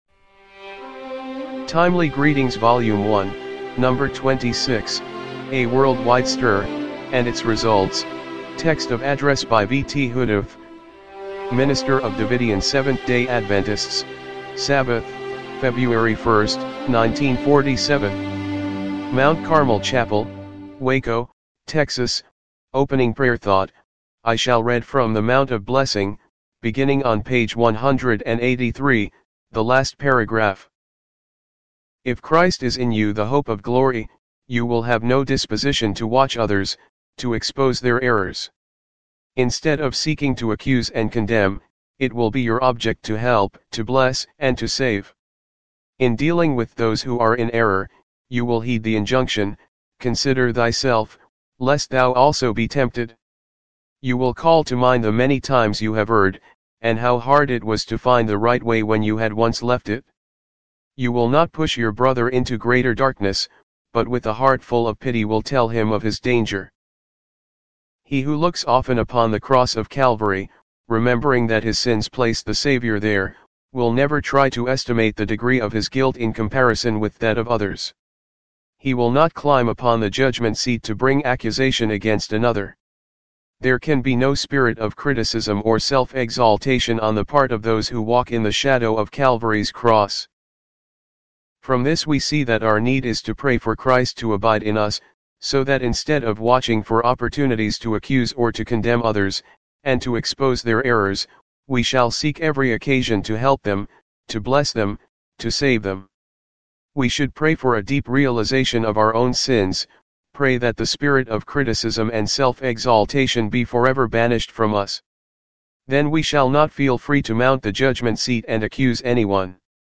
timely-greetings-volume-1-no.-26-mono-mp3.mp3